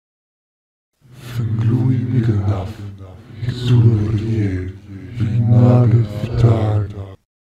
Заклинание преклонения перед Ктулху (произноси при встрече с ним)